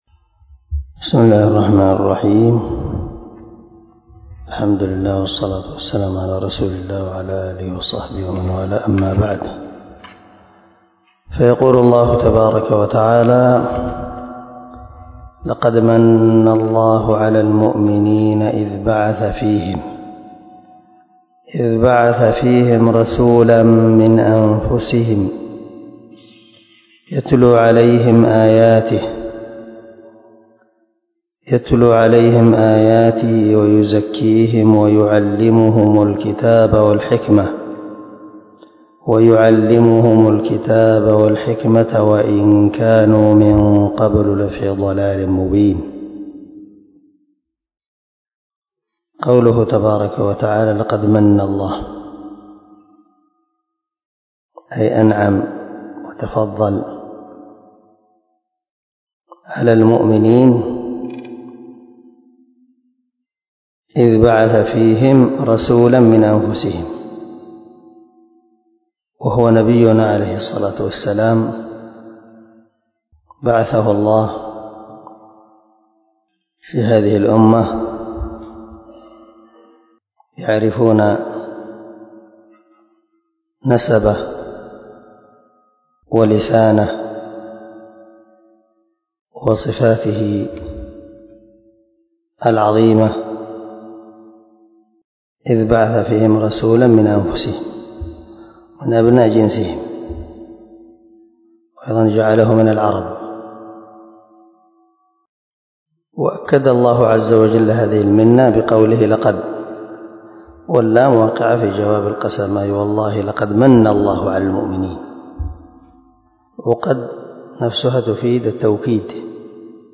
عنوان الدرس:
✒ دار الحديث- المَحاوِلة- الصبيحة.